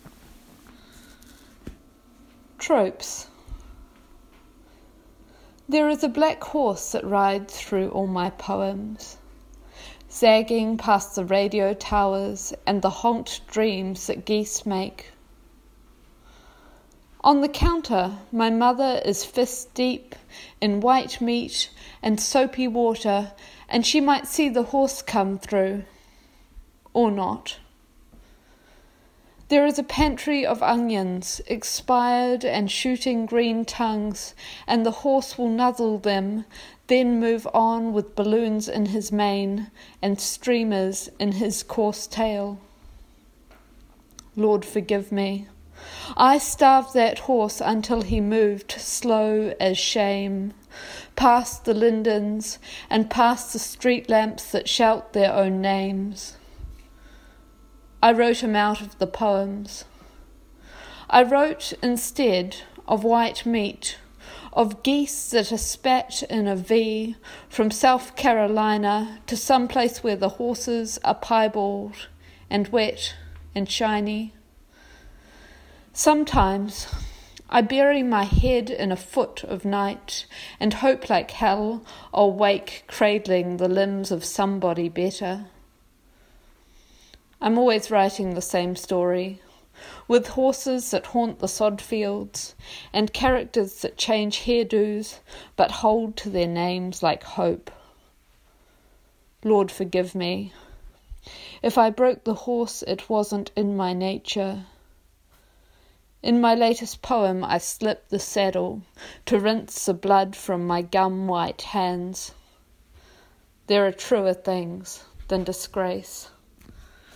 read ‘Tropes’:
tropes-recording.mp3